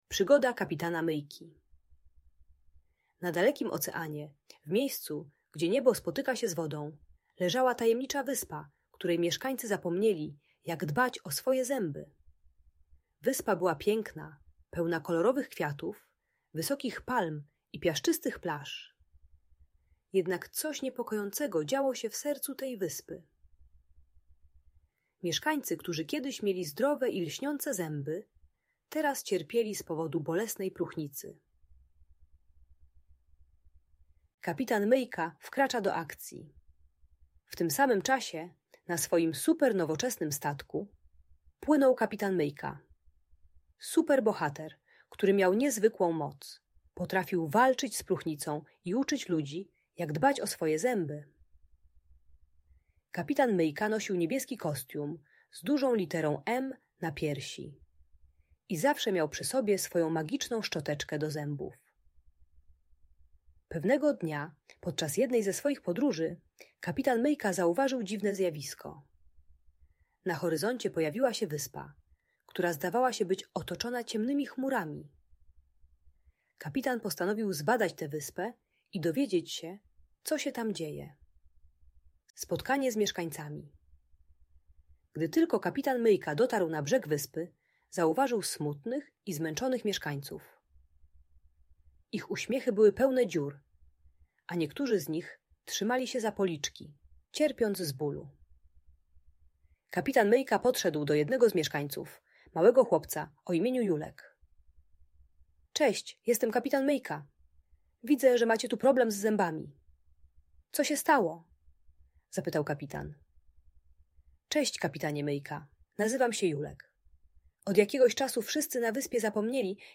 Przygoda Kapitana Myjki - Historia o Dbaniu o Zęby - Audiobajka